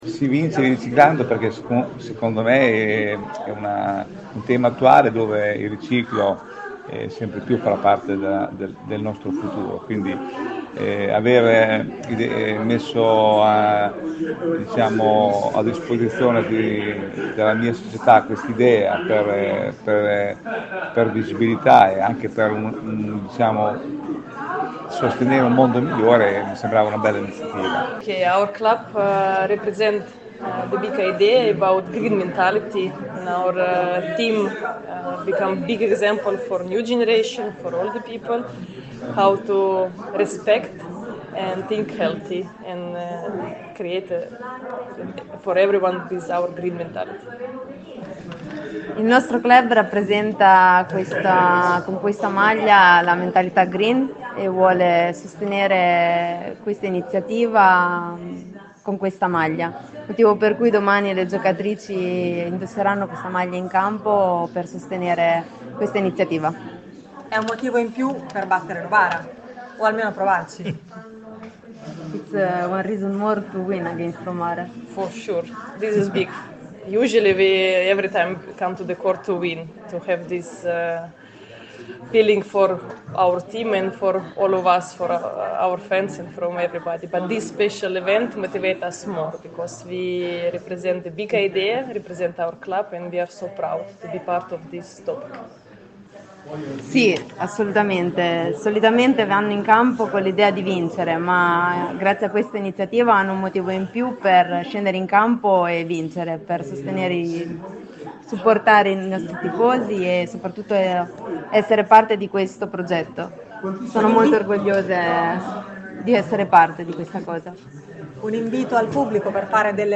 Fare della pallavolo e, in particolare, della Megabox Vallefoglia, un formidabile veicolo dell’idea di sostenibilità e rispetto dell’ambiente, innervando positivamente territori, scuole, università e tessuto imprenditoriale. È l’obiettivo del progetto “Ricicliamo, non consumiamo”, presentato questa mattina a Palazzo Ciacchi, sede di Confindustria Pesaro Urbino. Le interviste
a margine della conferenza.